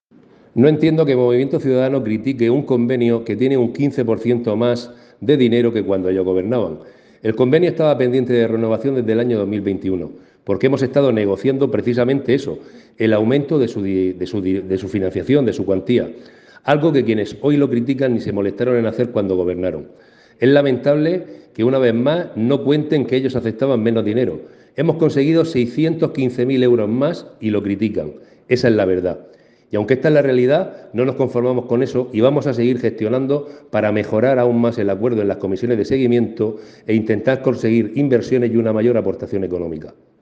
Enlace a Declaraciones de Diego Ortega.